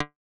pluckSynth.wav